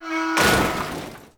bf_metal_large.wav